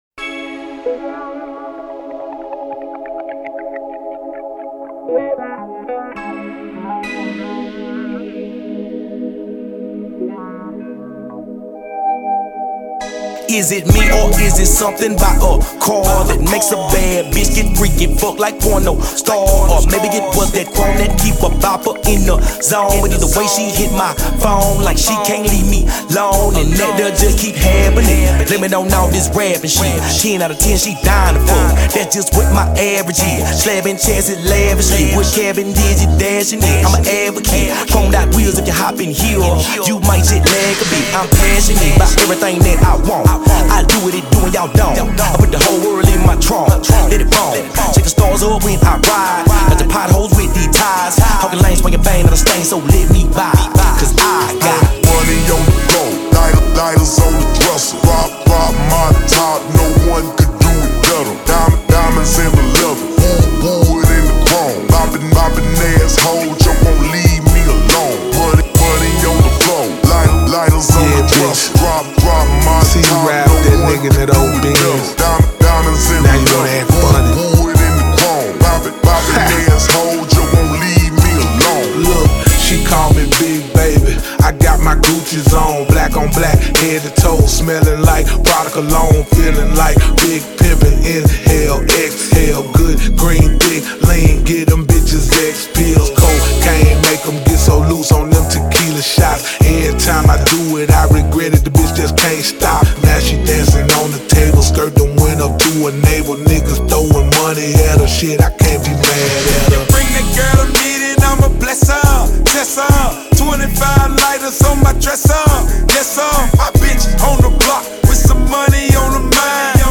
The production has the gritty funk